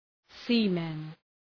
Shkrimi fonetik {‘si:mən}
seamen.mp3